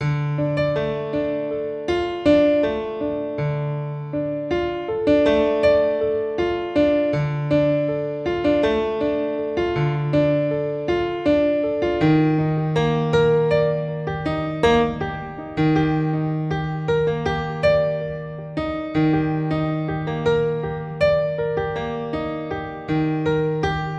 嘻哈钢琴80bpm Gminor
描述：嘻哈钢琴 80bpm Gminor
标签： 80 bpm Hip Hop Loops Piano Loops 2.02 MB wav Key : G
声道立体声